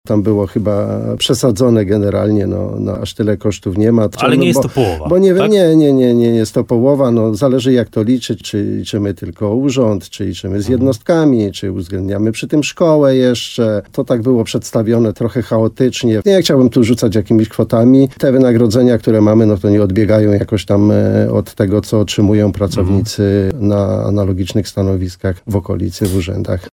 – Myślę, że mijają z prawdą – powiedział w programie Słowo za Słowo w radiu RDN Nowy Sącz wójt gminy Szczawa Janusz Opyd.